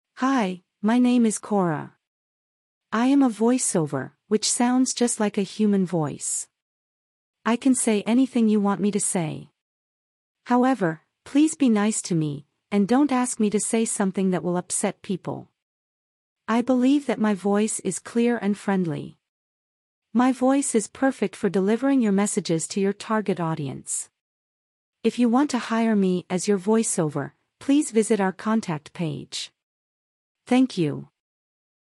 voiceover
Voiceover - Female